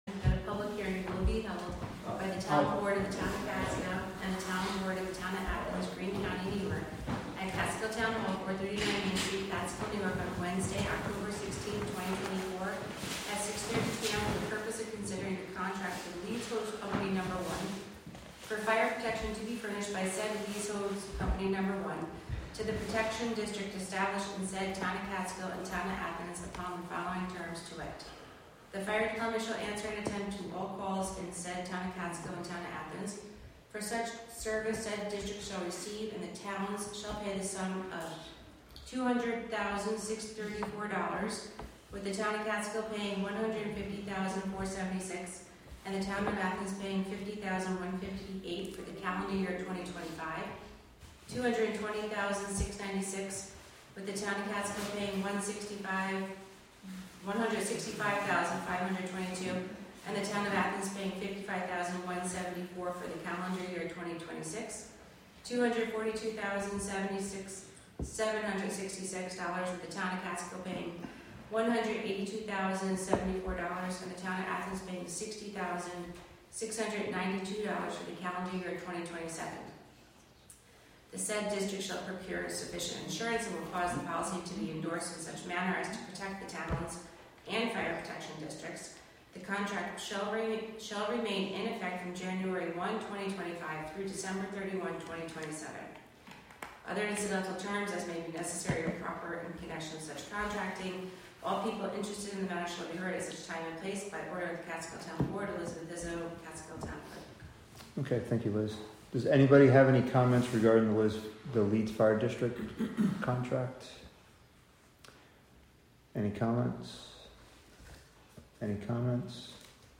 Live from the Town of Catskill: October 16, 2024 Catskill Town Board Meeting (Audio)